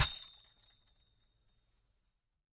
打击乐 " TAMBOURINE
描述：铃鼓打在一边
Tag: 节奏 手鼓 打击乐